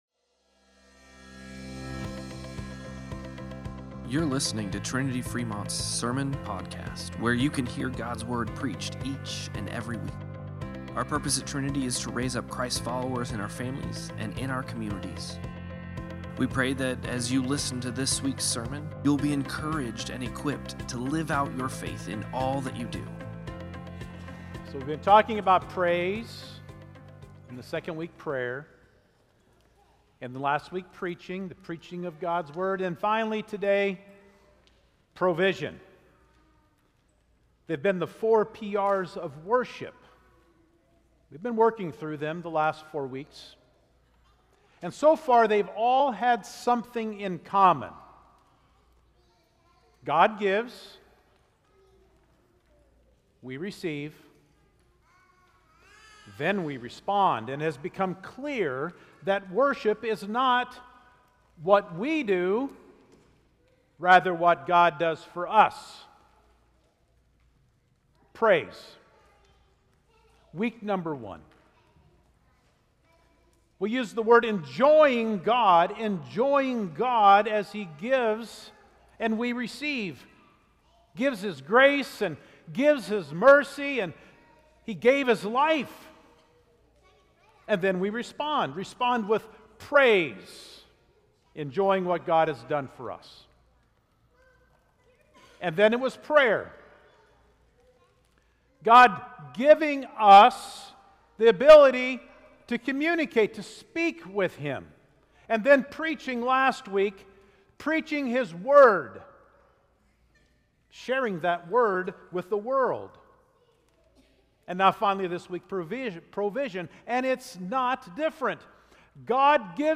Sermon-Podcast-08-24.mp3